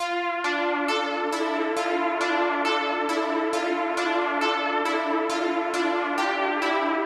冷合成物
Tag: 136 bpm Trap Loops Synth Loops 1.19 MB wav Key : D